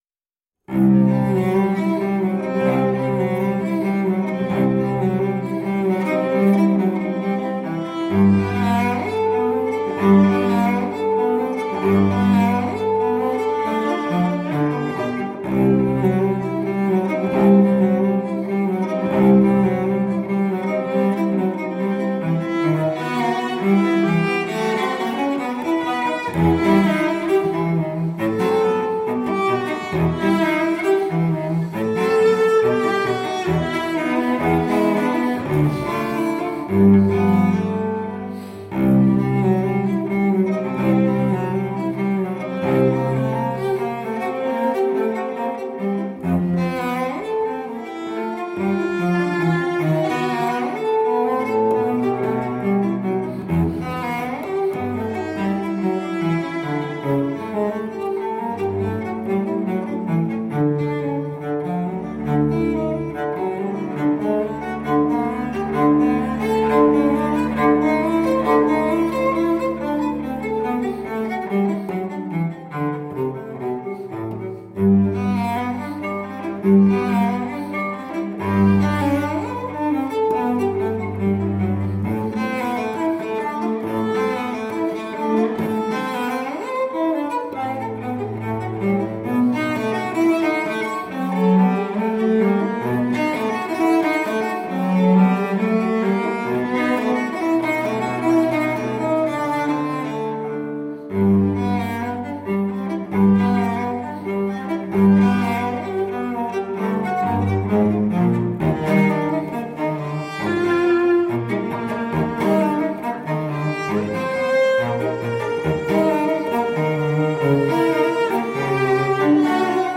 Classical, Romantic Era, Instrumental Classical, Cello